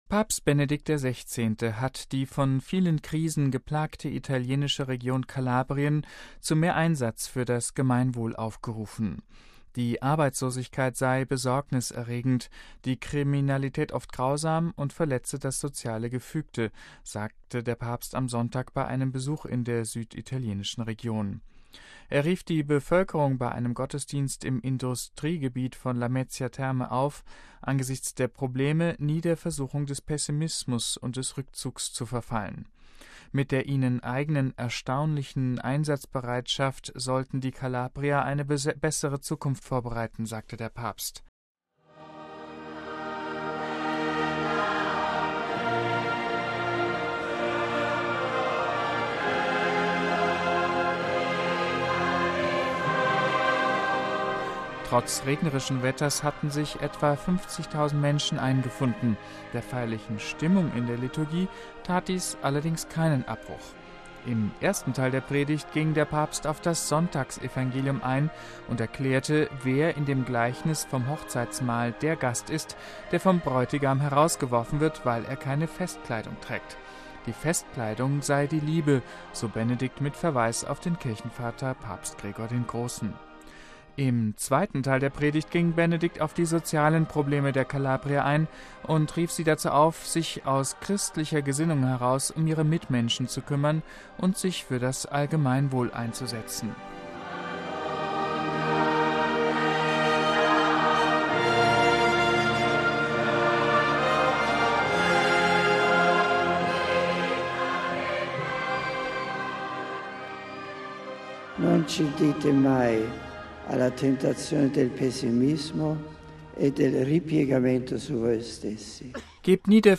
Er rief die Bevölkerung bei einem Gottesdienst im Industriegebiet von Lamezia Terme auf, angesichts der Probleme nie der Versuchung des Pessimismus und des Rückzugs zu verfallen.
Trotz regnerischen Wetters hatten sich etwa 50.000 Menschen eingefunden. Der feierlichen Stimmung in der Liturgie tat dies allerdings keinen Abbruch.